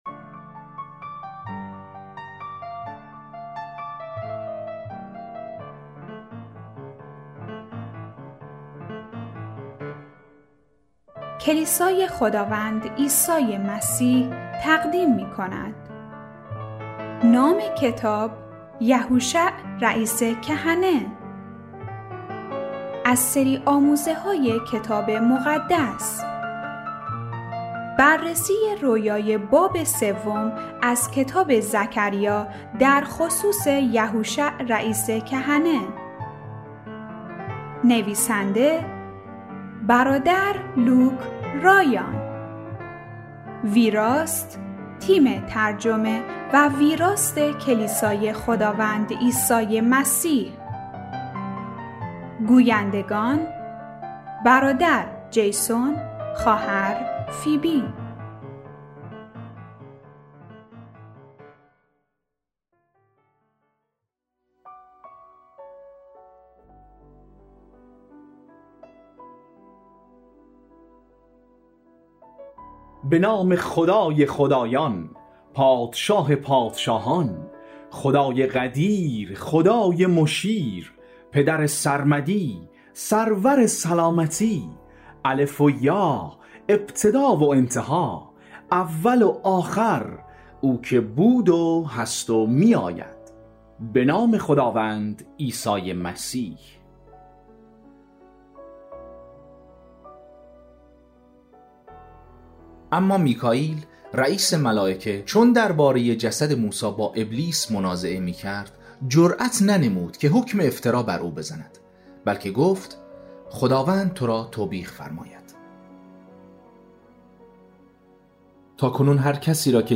پخش آنلاین و دانلود کتاب صوتی یهوشع رئیس کهنه